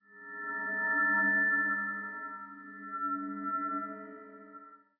ghost_idle2.wav